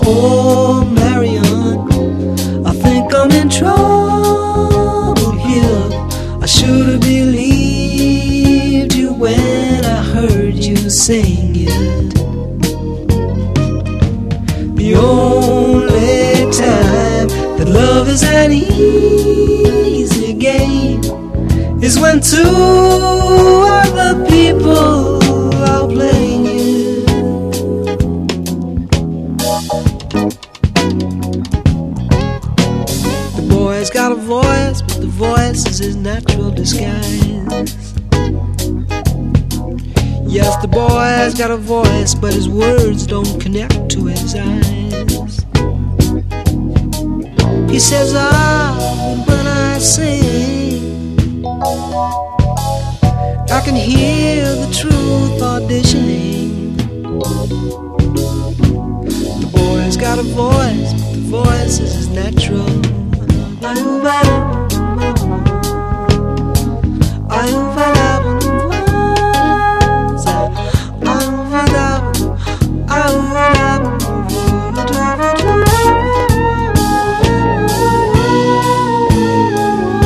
ROCK / S.S.W./A.O.R. / FREE SOUL / MODERN POP
コーラス/ハーモニーの美しさも流石の一言。